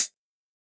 Index of /musicradar/ultimate-hihat-samples/Hits/ElectroHat B
UHH_ElectroHatB_Hit-20.wav